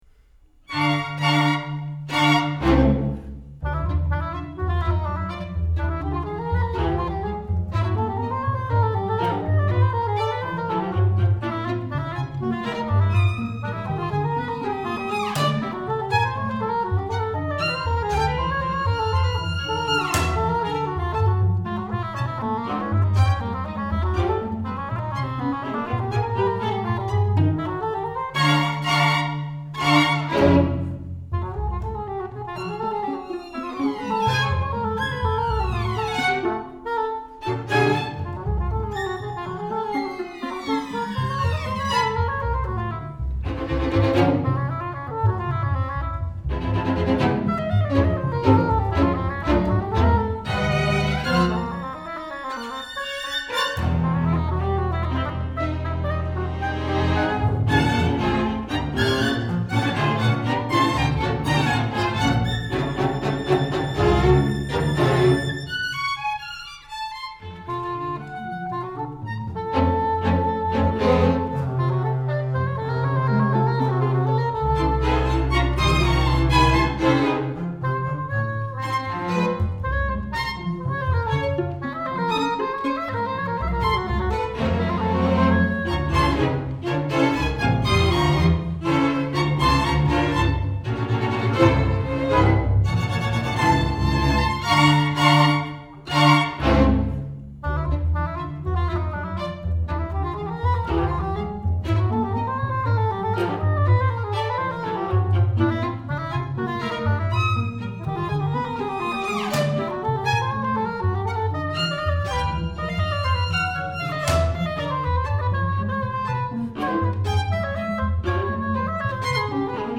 • Thriller (00:00);
• Horror (00:00)